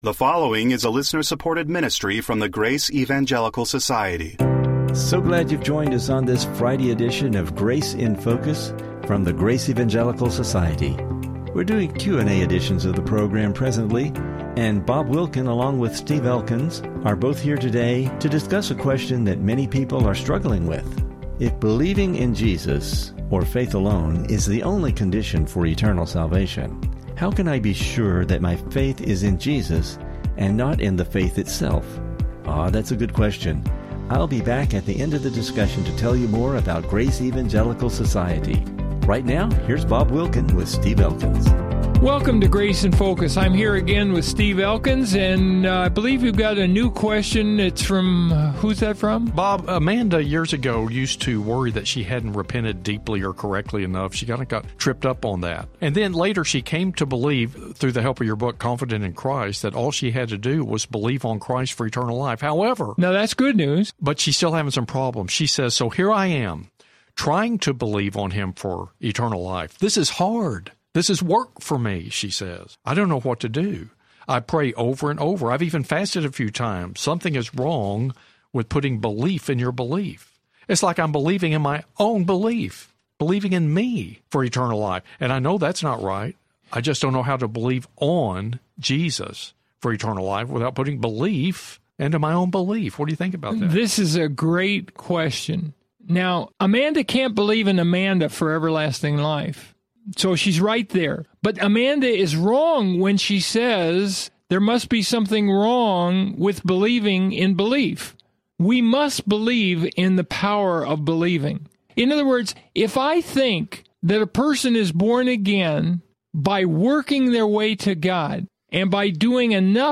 Today, the guys will be answering a question related to the faith alone message. Is it possible for a person to believe in their belief for eternal life?